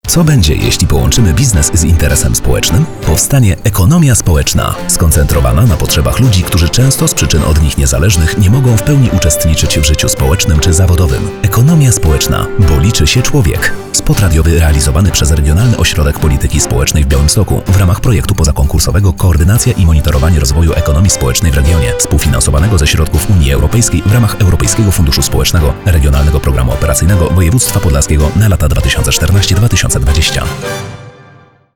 Pierwszy spot reklamowy: